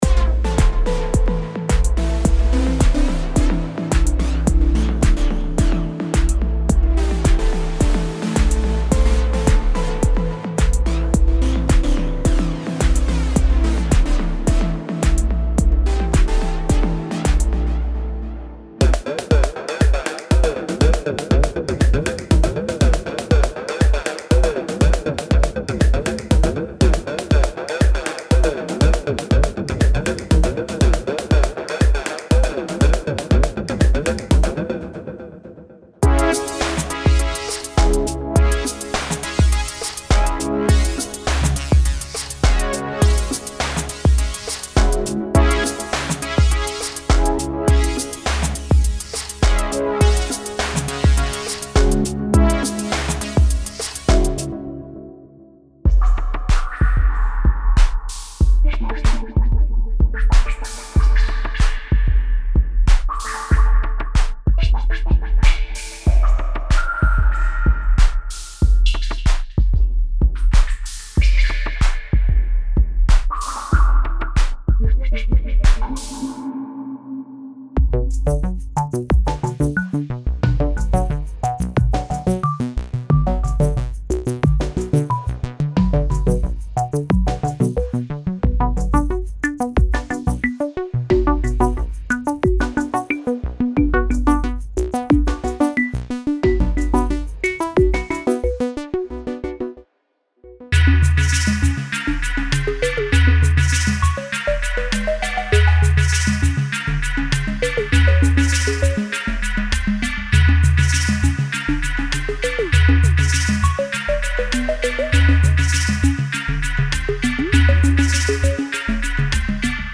With drums